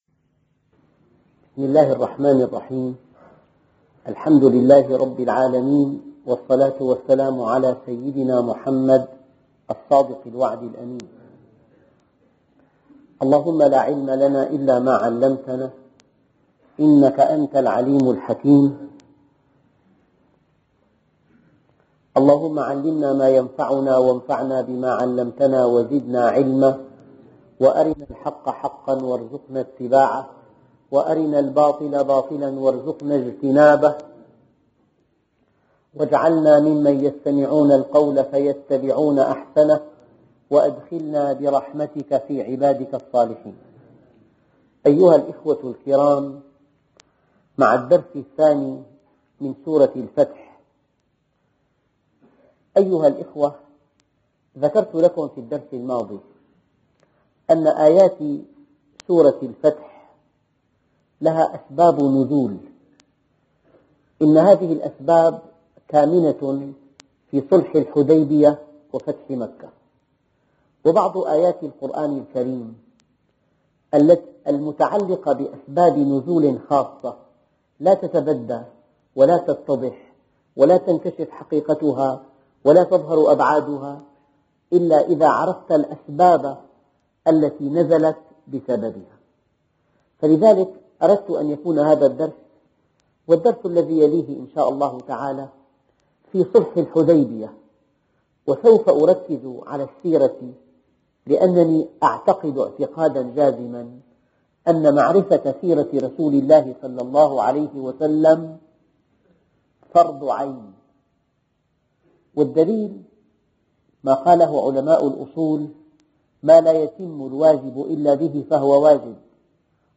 أرشيف الإسلام - أرشيف صوتي لدروس وخطب ومحاضرات د. محمد راتب النابلسي